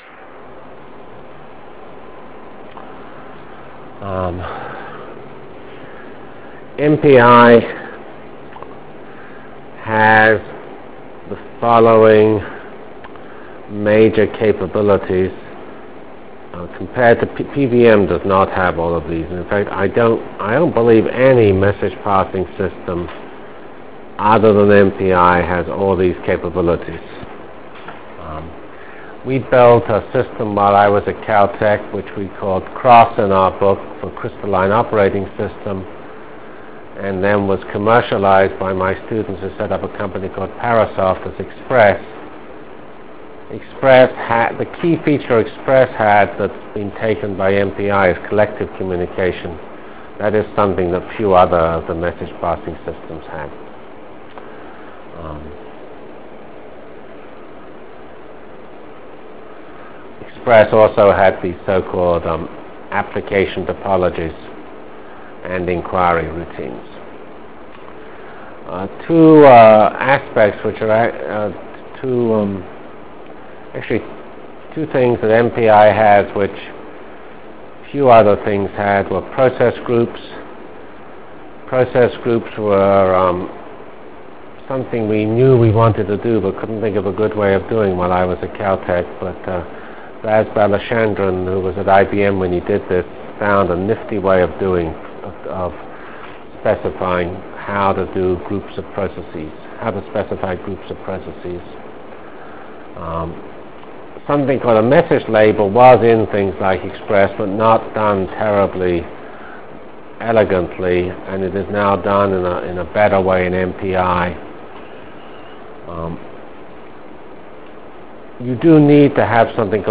From CPS615-Initial Lecture on MPI ending with discussion of basic MPI_SEND Delivered Lectures of CPS615 Basic Simulation Track for Computational Science -- 31 October 96. by Geoffrey C. Fox